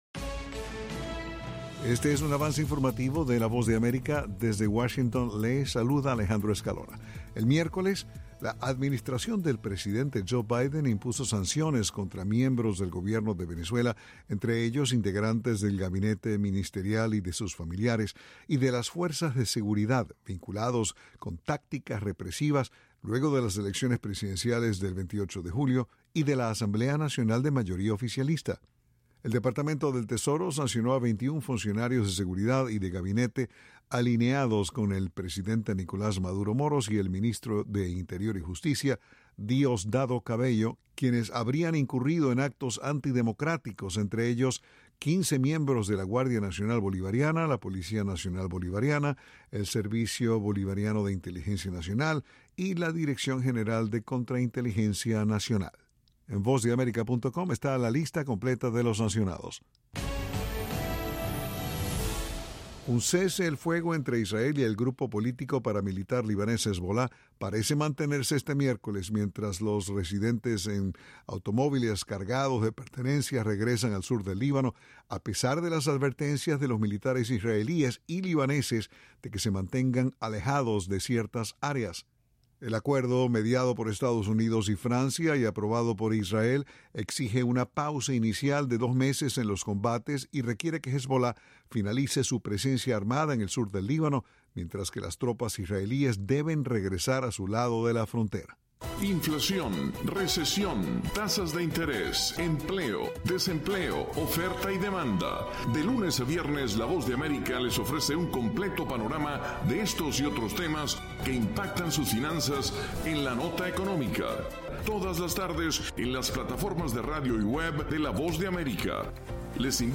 Avance Informativo
Este es un avance informativo de la Voz de América.